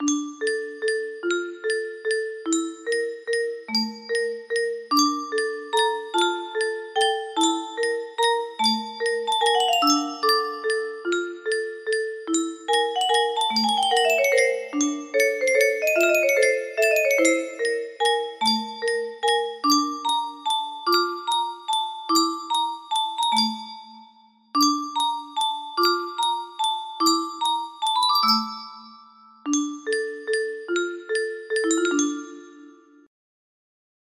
The Puppet in The Closet music box melody